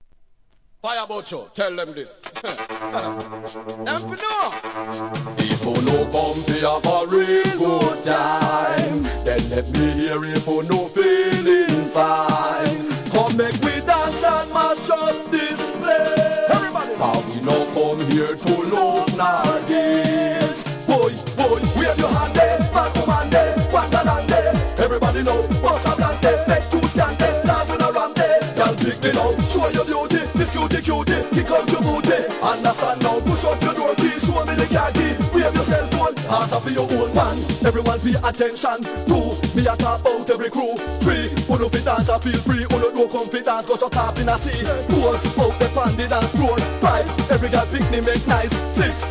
REGGAE
激しい四つ打ちにカントリー調のメロディーが激高揚感なダンス定番!!